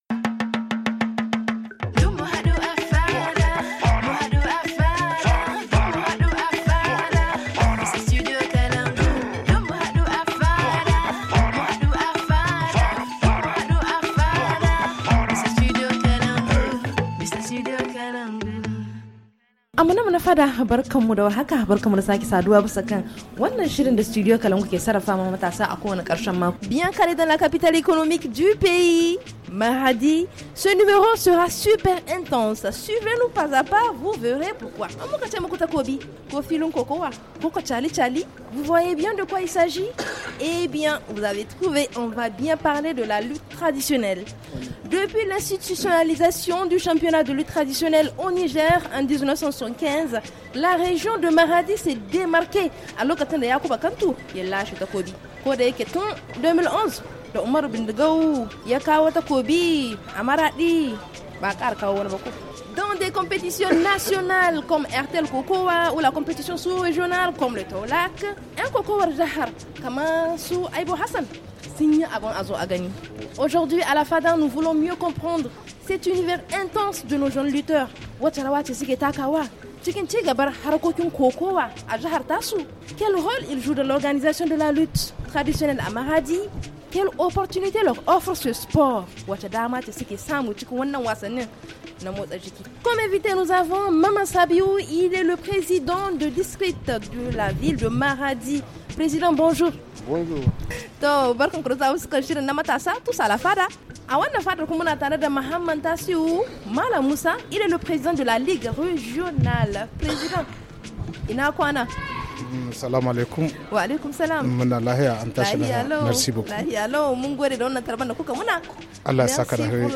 La Fada est présente à Maradi, la capitale économique du Niger.